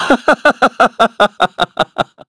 Shakmeh-Vox_Happy2_kr.wav